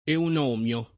Eunomio [ eun 0 m L o ]